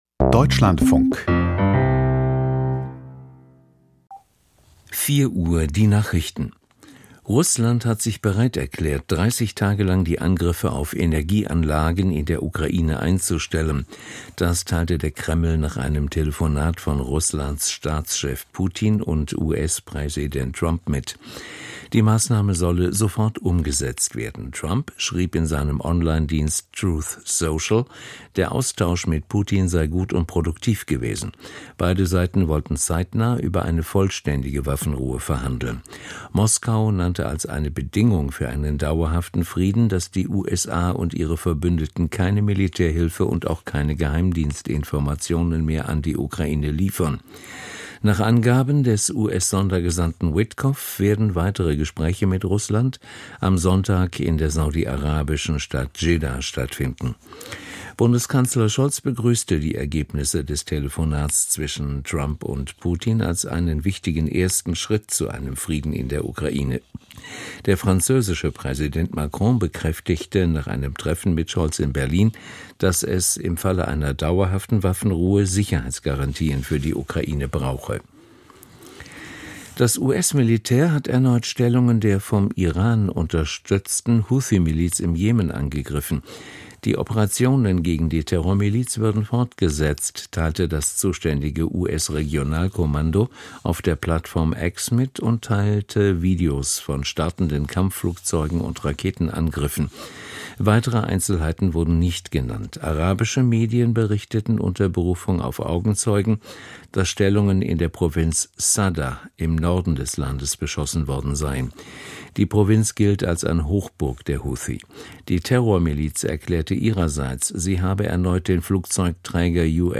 Die Nachrichten 9,414 subscribers updated 1h ago Подписаться Подписка добавлена Прослушать Воспроизведение Поделиться Отметить все как (не)прослушанные ...